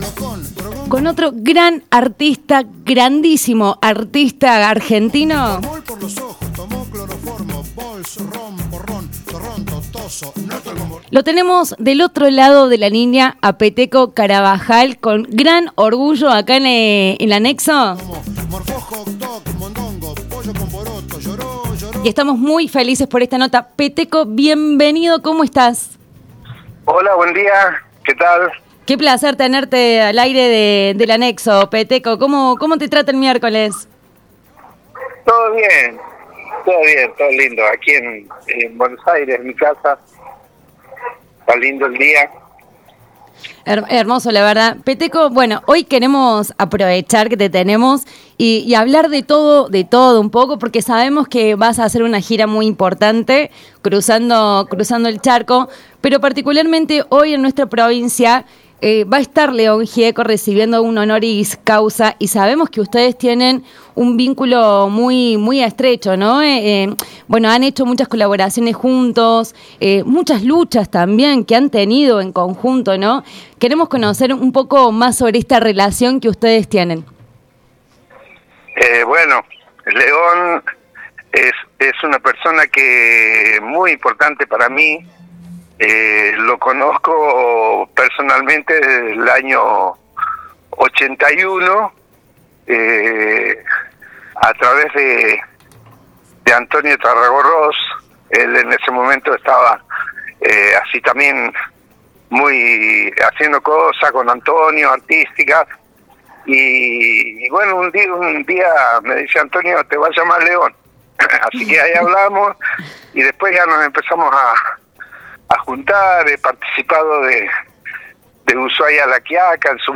Sereno, con ese tono inconfundible que mezcla el decir pausado del norte y la firmeza del hombre de convicciones, el músico santiagueño compartió reflexiones profundas sobre su vínculo con León Gieco, la cultura como herramienta de transformación social y los desafíos que atraviesa la Argentina actual.